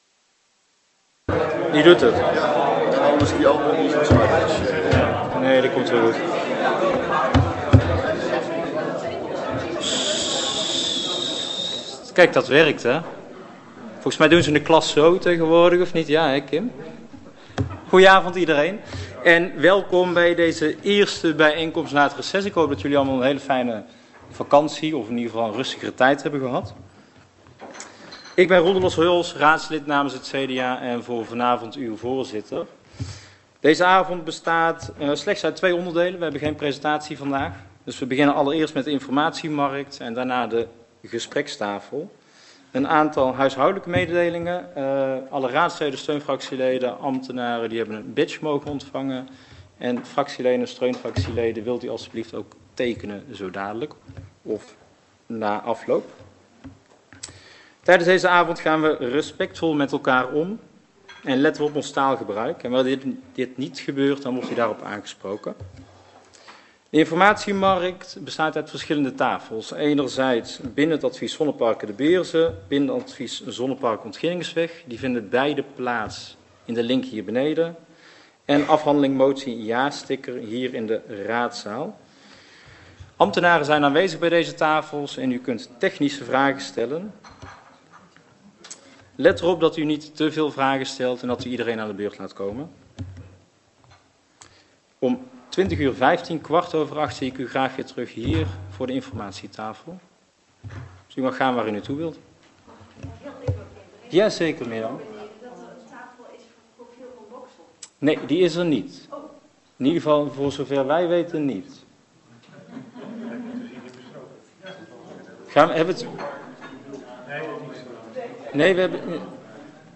Deze bijeenkomst vindt plaats in het gemeentehuis.